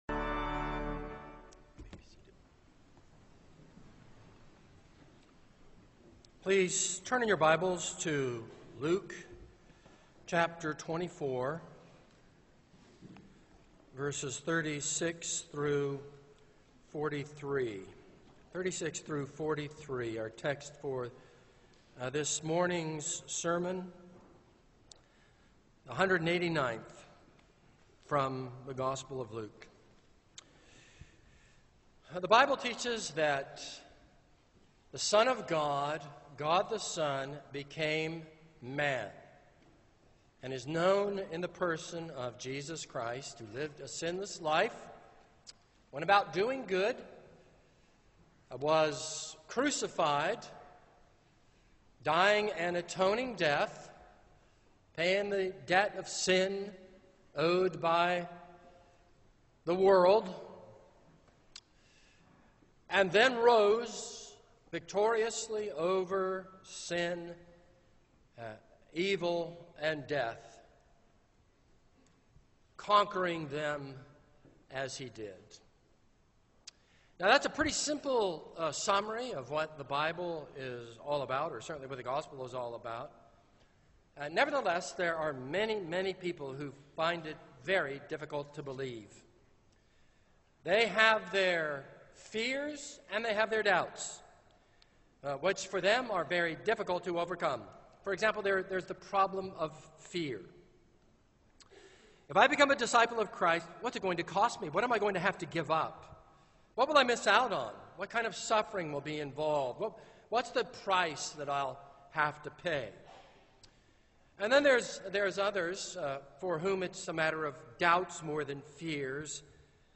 This is a sermon on Luke 24:36-43.